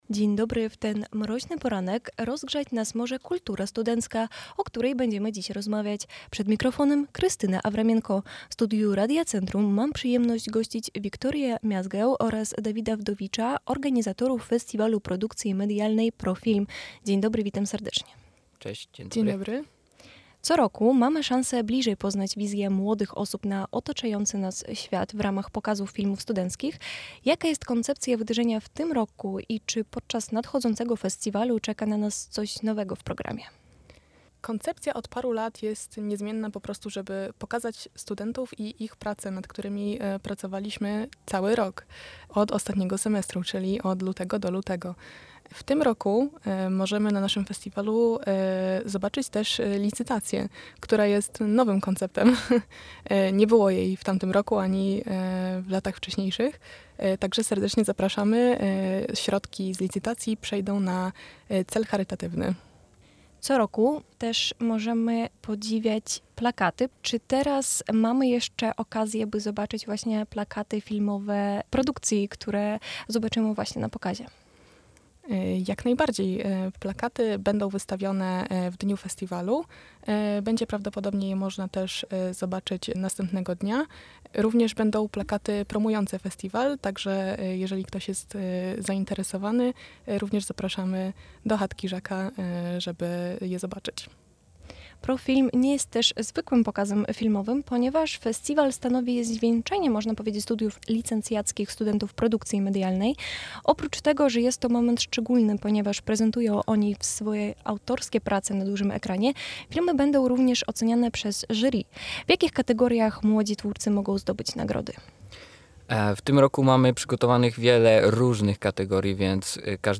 O propozycjach, jakie zobaczymy podczas nadchodzącego Festiwalu PRO-Film, rozmawialiśmy podczas Porannej Rozmowy Radia Centrum.